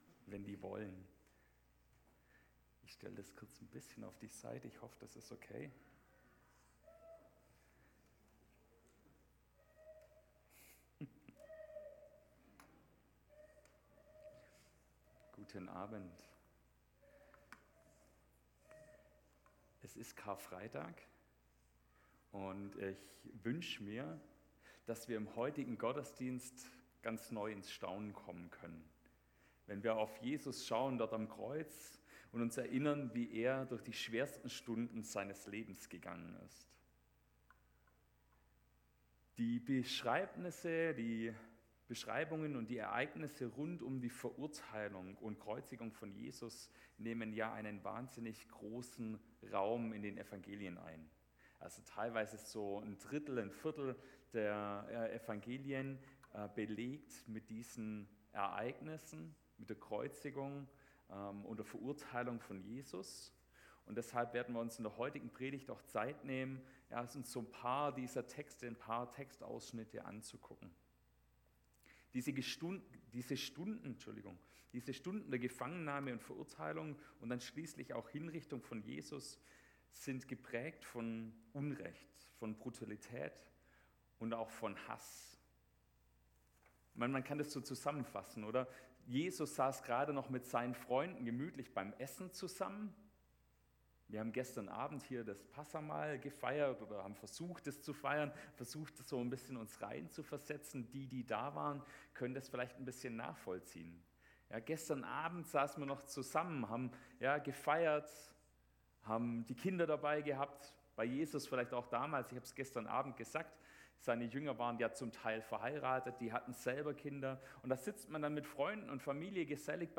Gottesdienst am 29.03.2024